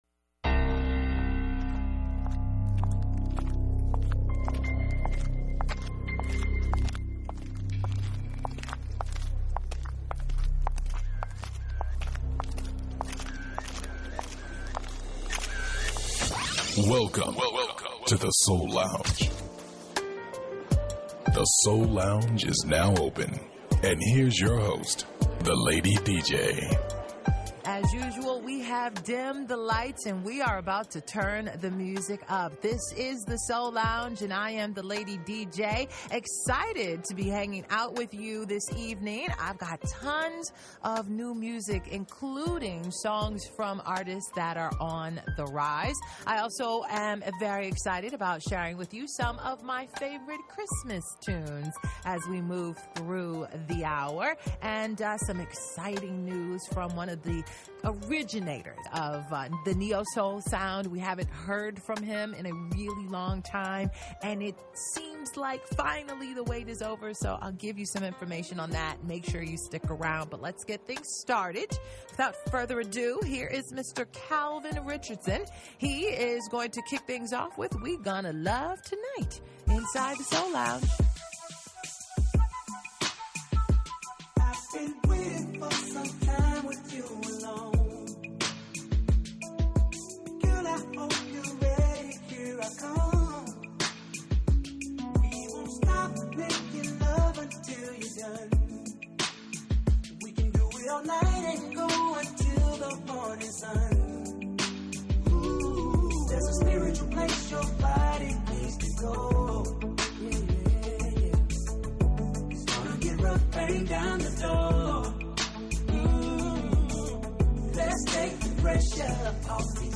Neo-Soul
conscious Hip-Hop
Classic Soul